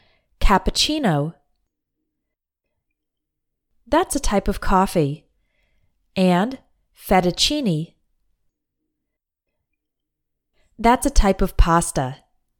Pronounce CC like CH (in a few words borrowed from Italian)